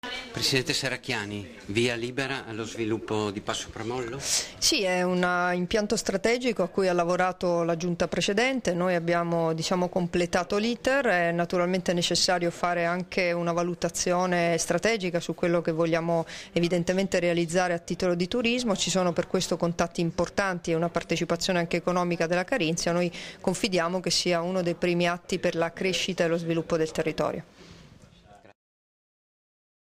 Dichiarazioni di Debora Serracchiani (Formato MP3) [479KB]
rilasciate a margine dell'approvazione, da parte della Giunta regionale, della delibera del Progetto Pramollo, a Udine il 30 agosto 2013